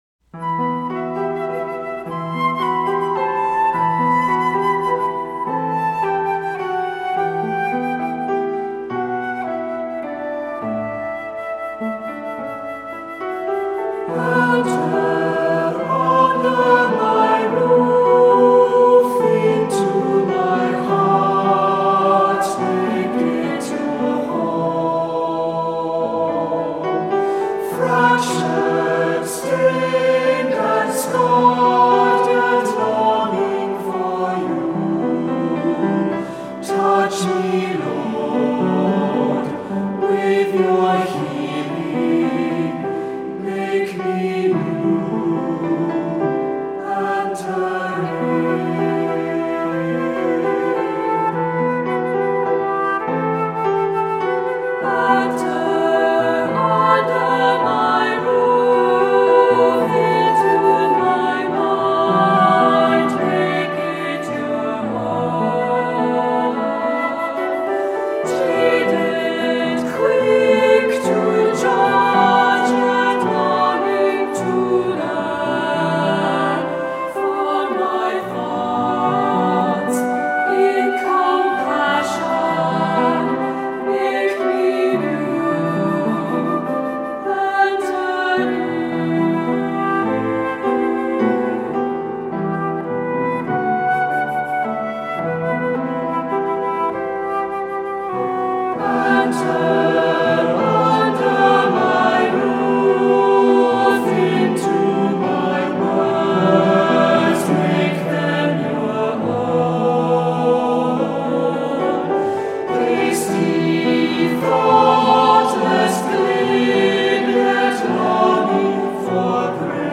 Voicing: Two-part equal; Cantor; Assembly